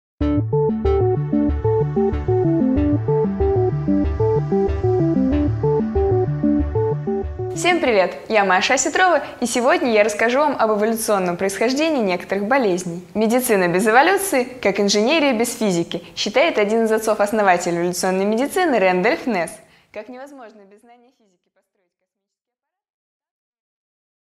Аудиокнига 5 минут О болезнях и эволюции | Библиотека аудиокниг